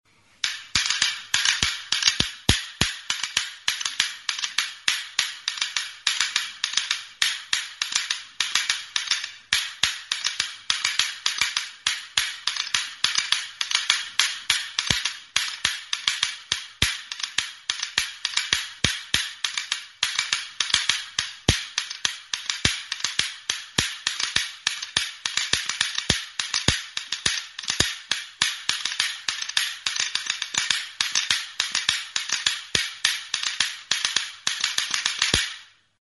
Idiófonos -> Golpeados -> Indirectamente
Grabado con este instrumento.
Astintzerakoan, bi kanabera erdiek elkar jotzen dute, hotsa emanez.